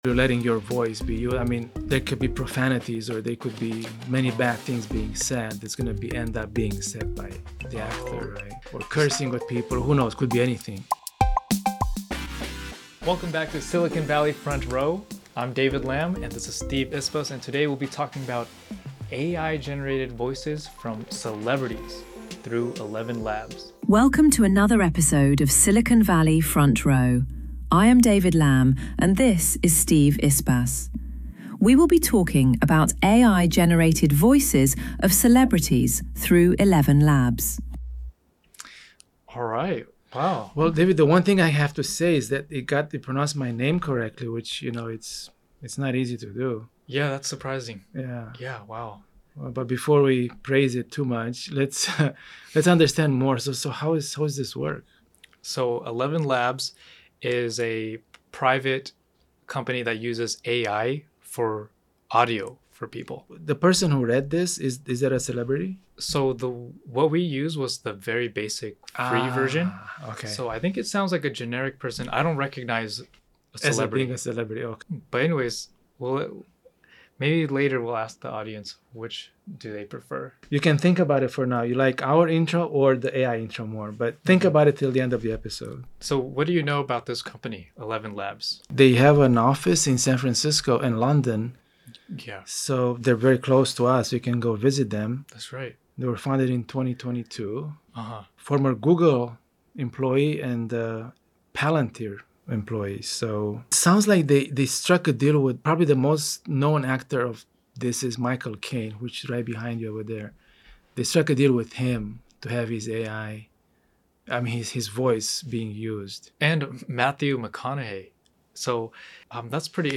Live demo of ElevenLabs AI voice generator